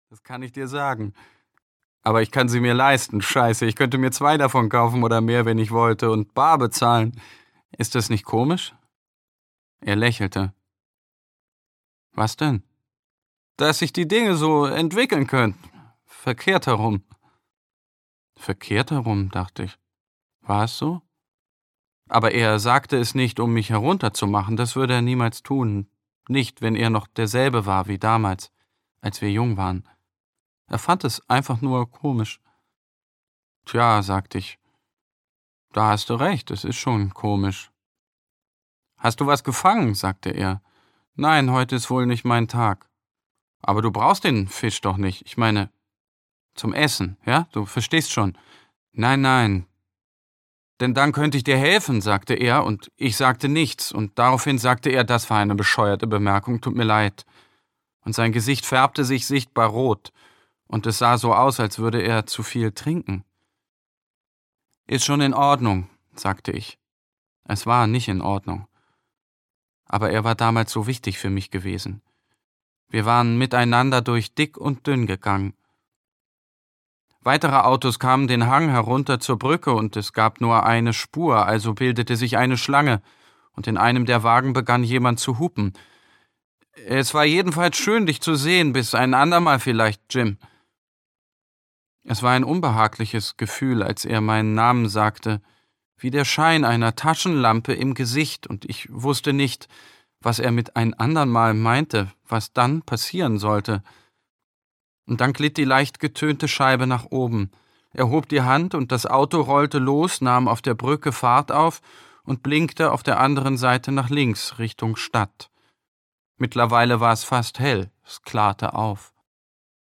Nicht mit mir - Per Petterson - Hörbuch